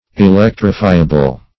Search Result for " electrifiable" : The Collaborative International Dictionary of English v.0.48: Electrifiable \E*lec"tri*fi`a*ble\, a. Capable of receiving electricity, or of being charged with it.
electrifiable.mp3